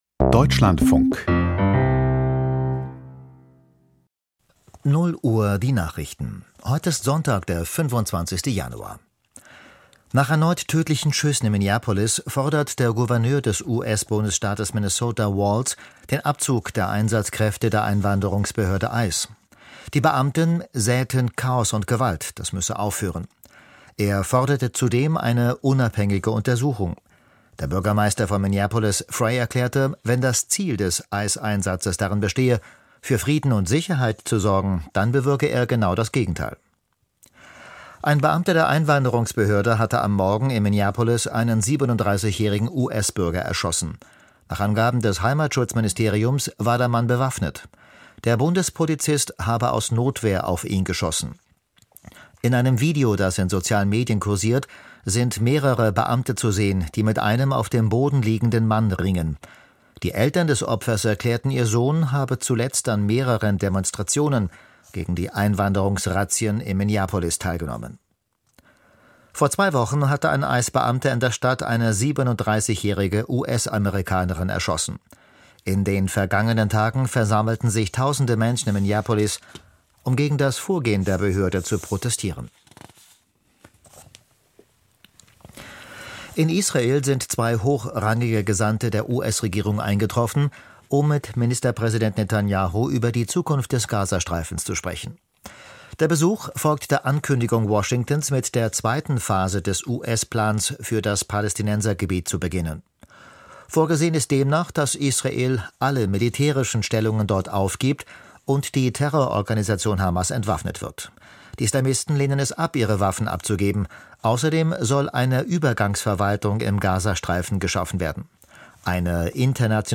Die Nachrichten vom 25.01.2026, 00:00 Uhr